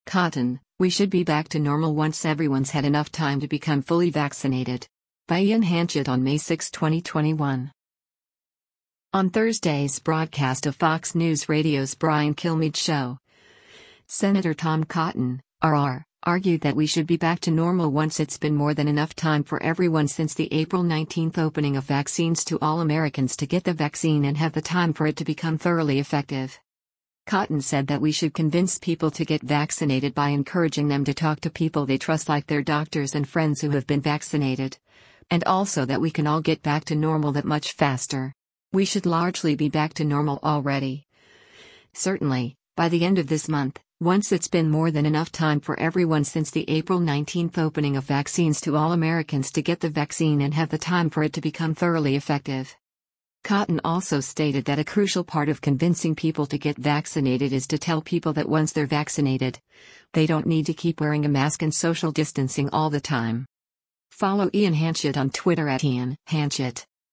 On Thursday’s broadcast of Fox News Radio’s “Brian Kilmeade Show,” Sen. Tom Cotton (R-AR) argued that we should be back to normal “once it’s been more than enough time for everyone since the April 19 opening of vaccines to all Americans to get the vaccine and have the time for it to become thoroughly effective.”